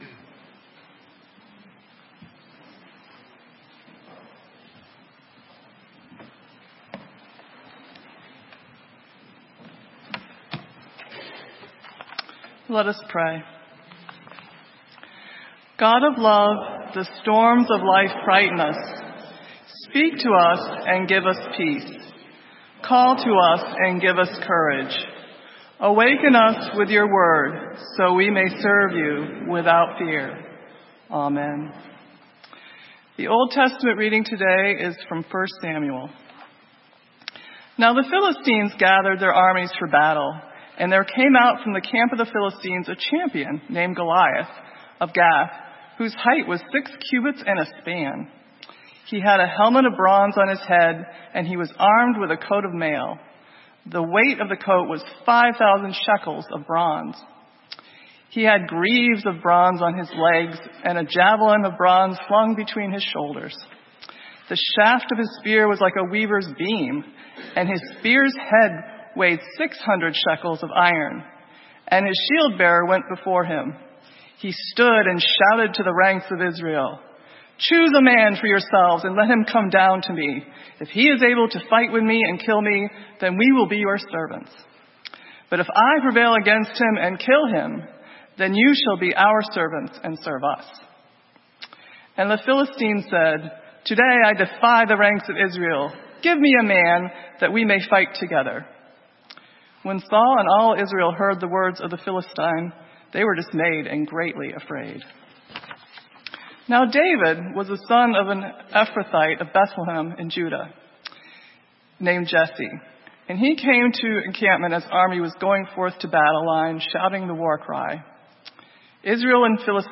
Sermon:Asleep in the boat - St. Matthew's UMC